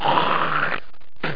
SPUCKEN.mp3